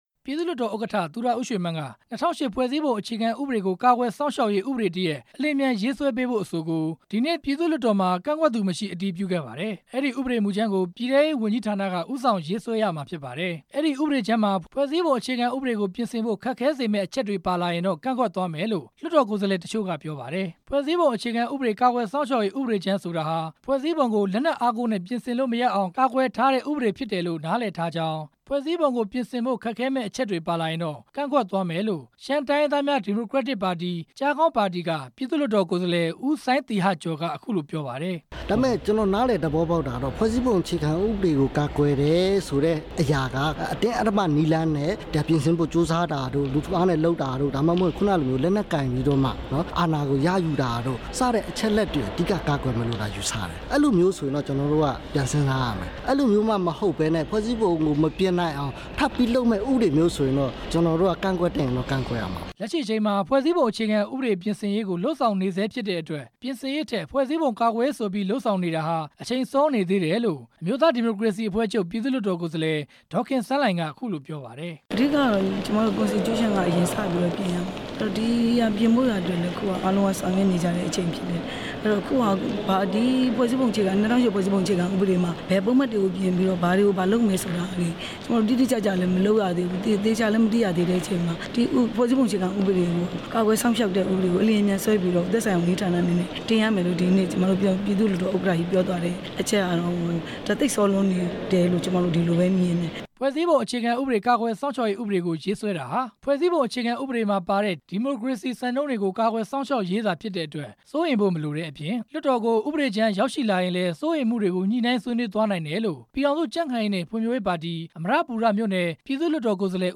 နေပြည်တော်က ပေးပို့ထားပြီး